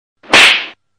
Slap Hard Meme Effect sound effects free download